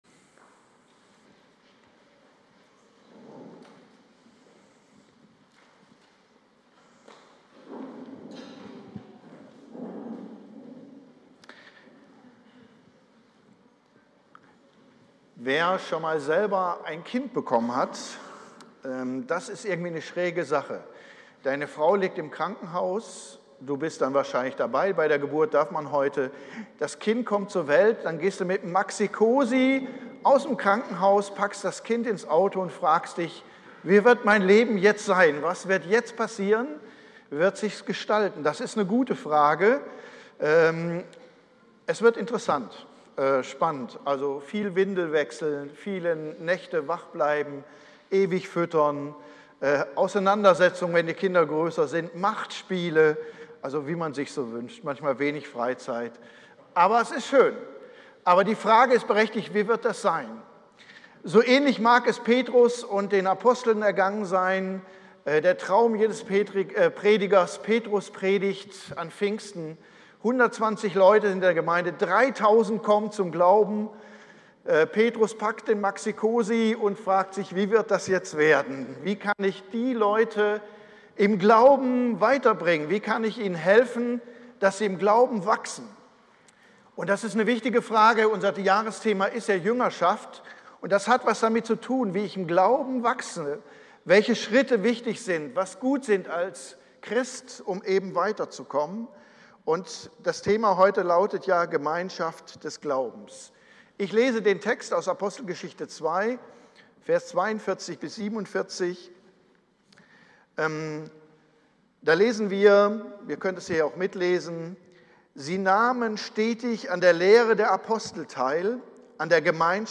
Predigt-20.07-online-audio-converter.com_.mp3